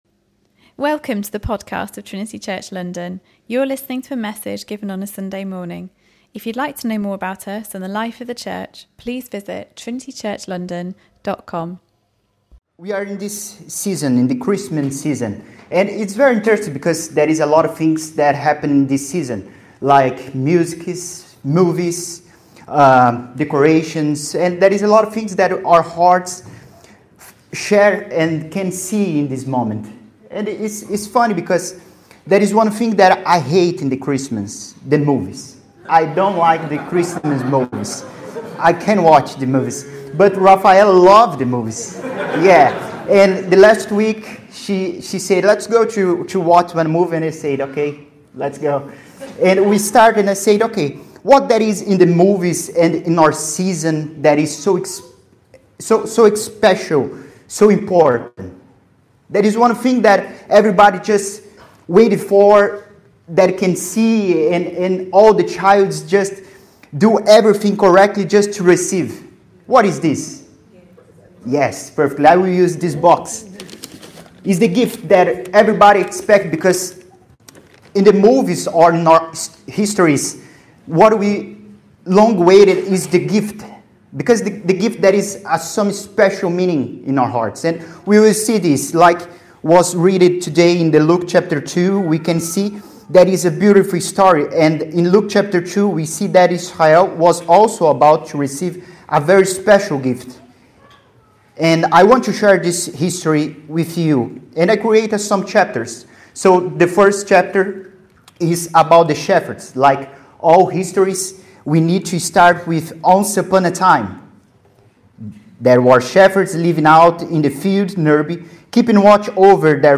Though we may receive many gifts this Christmas season.